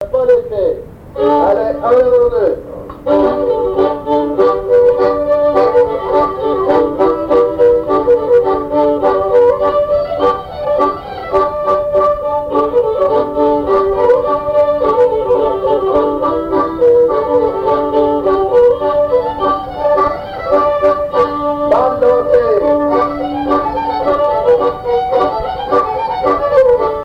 Rochetrejoux
danse : quadrille : pas d'été
Pièce musicale inédite